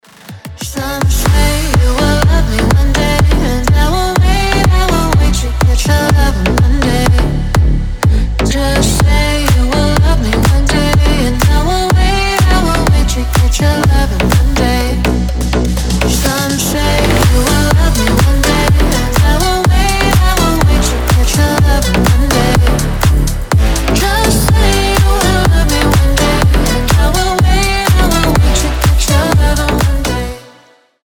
• Качество: 320, Stereo
EDM
future house